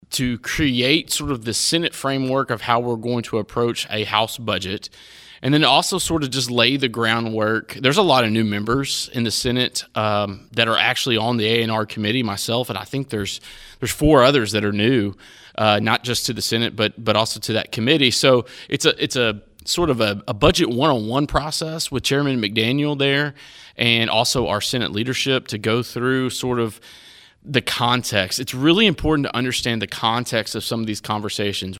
Third District State Senator Craig Richardson was joined by State Representatives Mary Beth Imes, Walker Thomas, and Myron Dossett during the Your News Edge Legislative Update program Saturday that was recorded at the H&R Agri-Power Pancake Day.